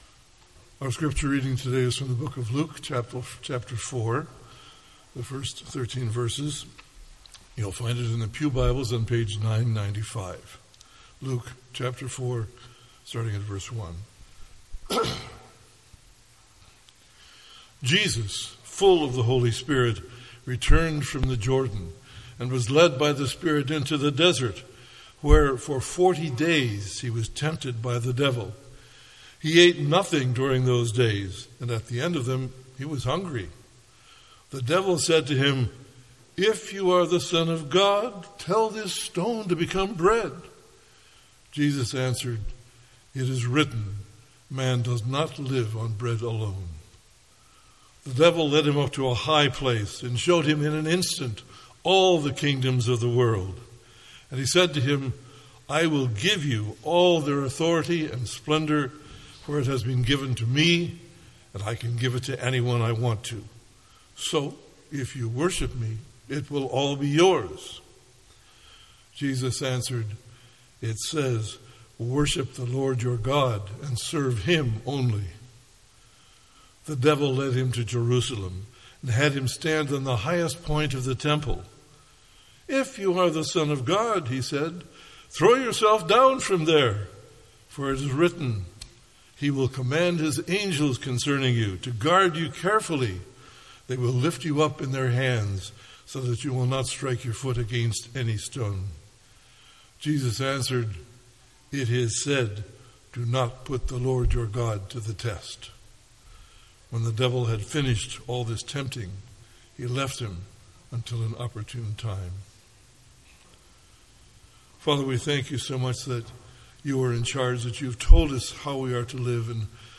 MP3 File Size: 19.5 MB Listen to Sermon: Download/Play Sermon MP3